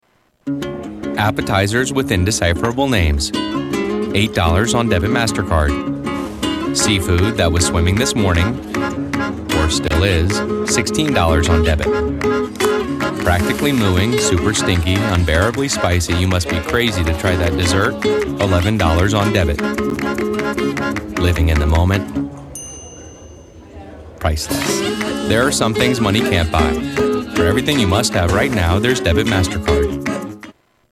Tags: Media MasterCard Advertisement Commercial MasterCard Clips